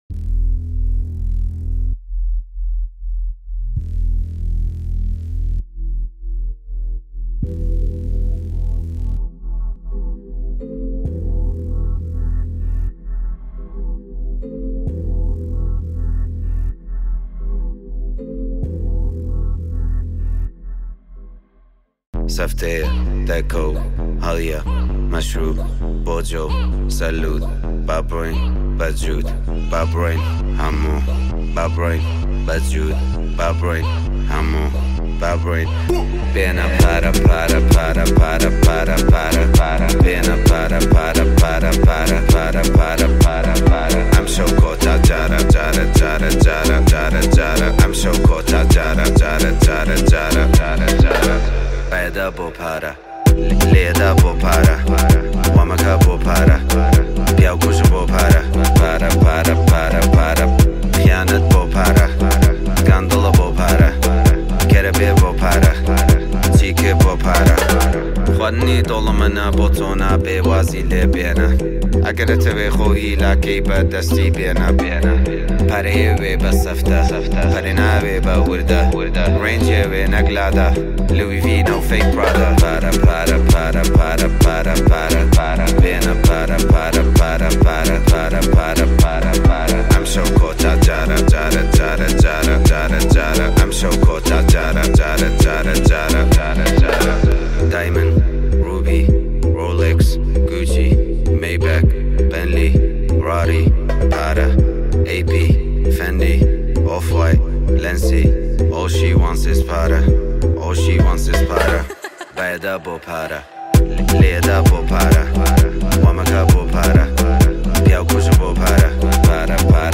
آهنگ کوردی رپ